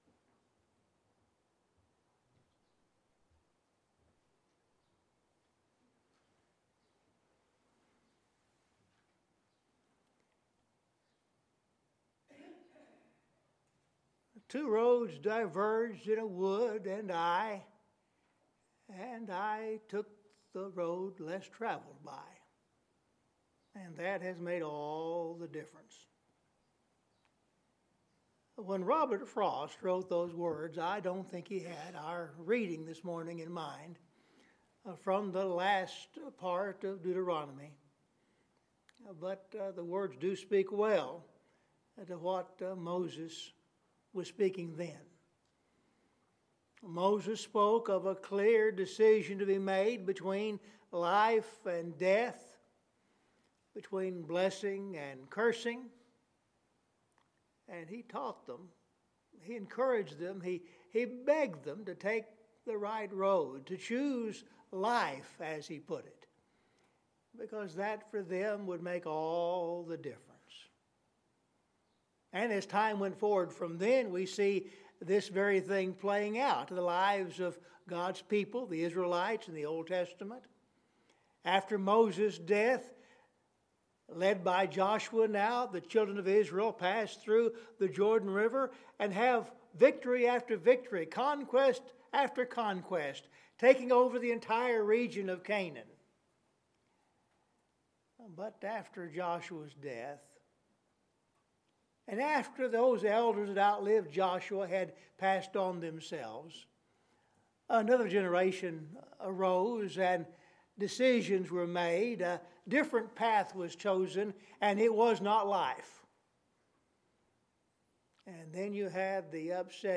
Scripture Reading – Deuteronomy 30:19,20